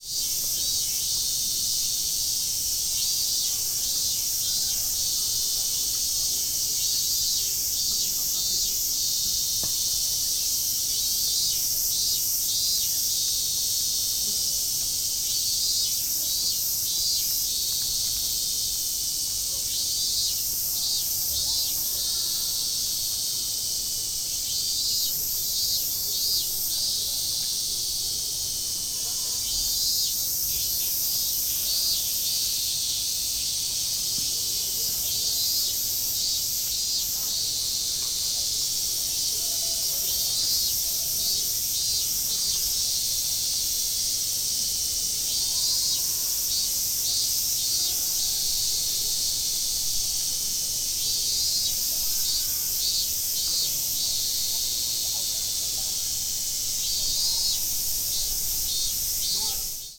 Fukushima Soundscape: Fukushima University